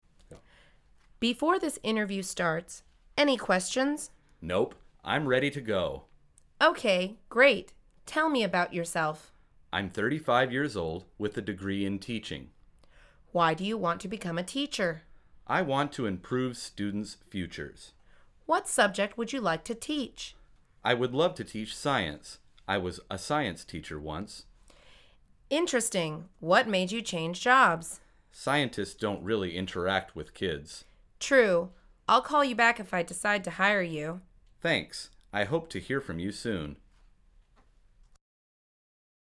مجموعه مکالمات ساده و آسان انگلیسی – درس شماره هفتم از فصل مشاغل: مصاحبه شغلی